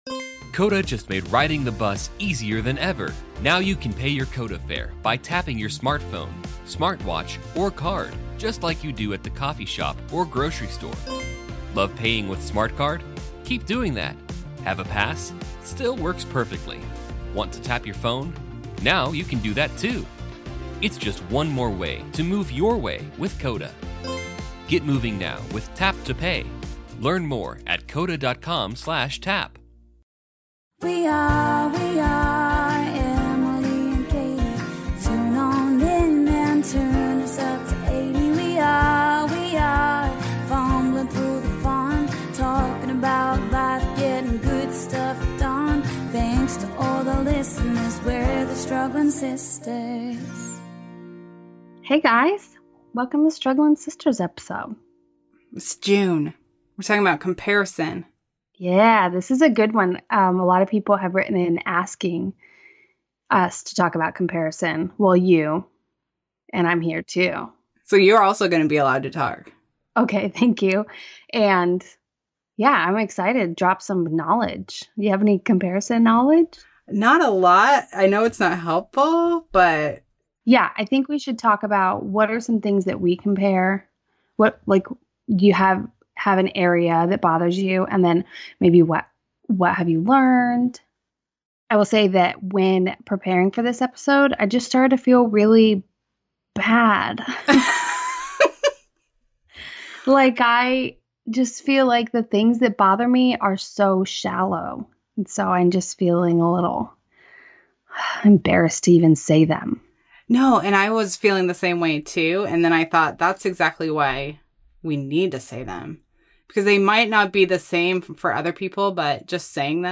Where do you feel like you fall short? Just a little honest conversation about comparison going on over here today.